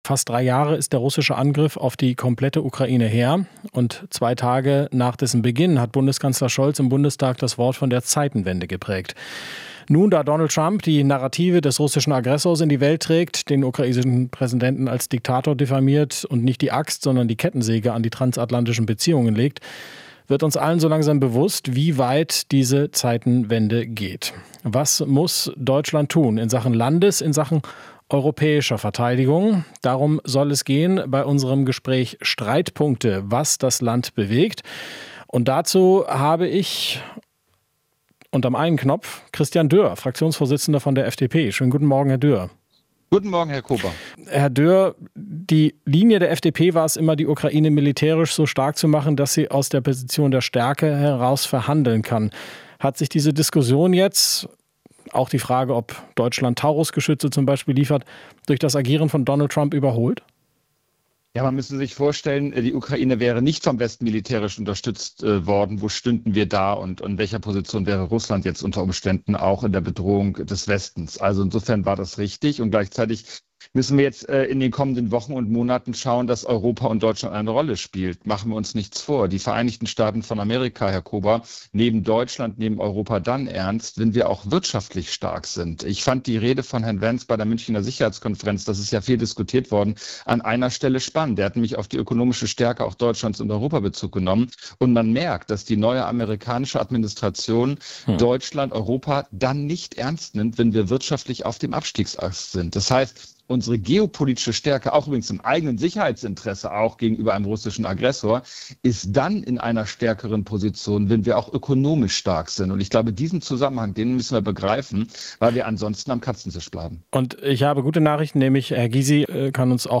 Über ihre Konzepte für Deutschlands Strategie zur Verteidigung streiten bei uns Gregor Gysi (Linke) und Christian Dürr (FDP).
Interview - Streitpunkt Verteidigung: Wie umgehen mit der äußeren Sicherheit?